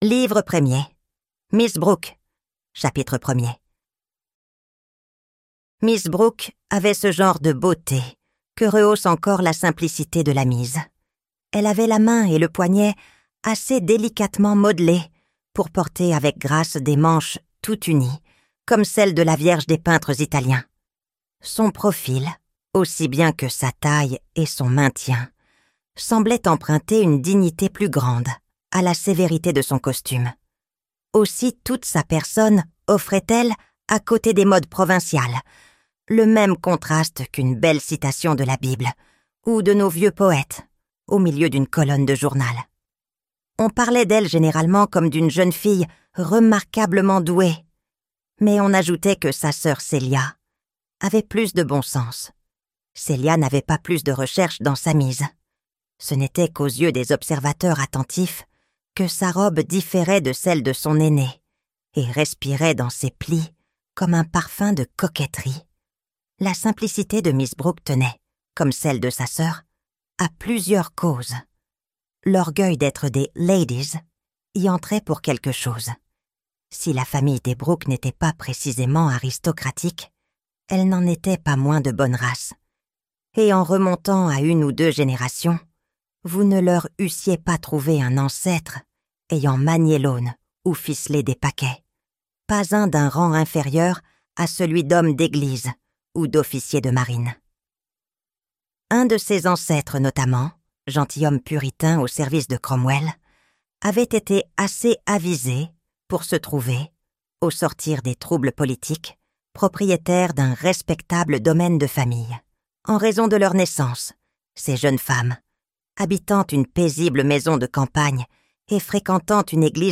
Middlemarch - Livre Audio